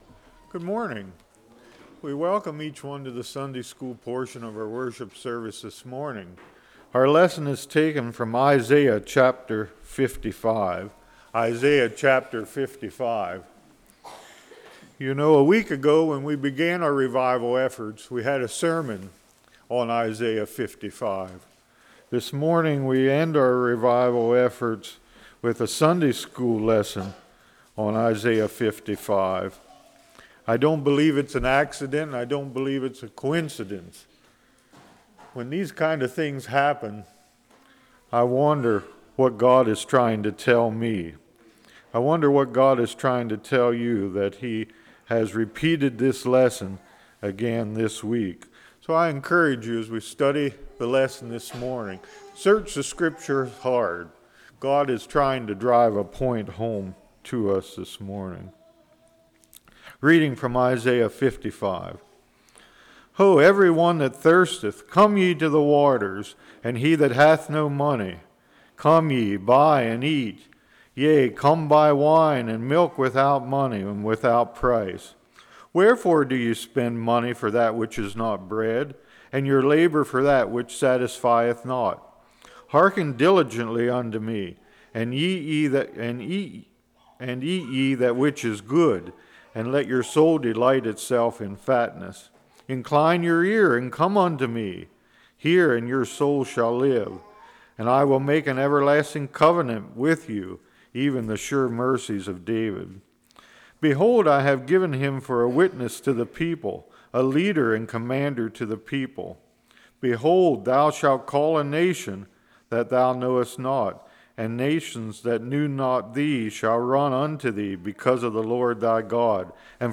Isaiah 55:1-13 Service Type: Sunday School Am I thirsty?